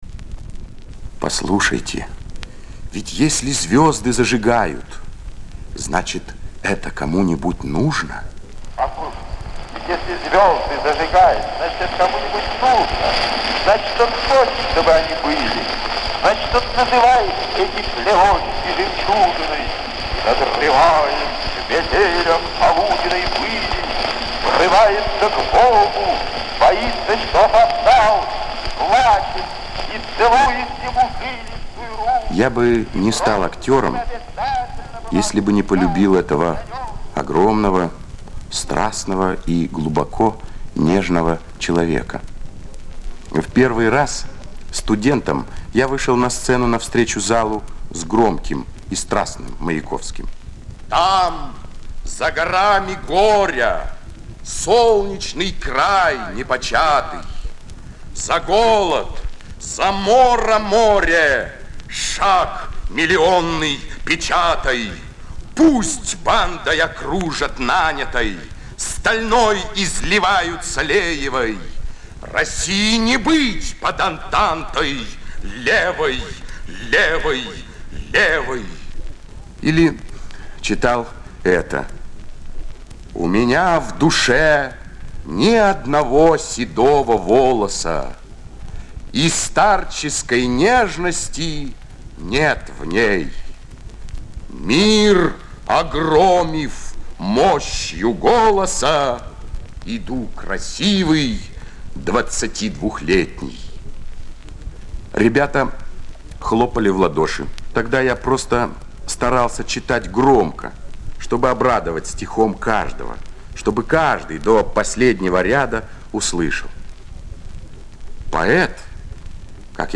Звуковая страница 1 - Чтение В.В.Маяковского комментирует Е.Урбанский.